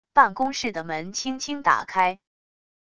办公室的门轻轻打开wav音频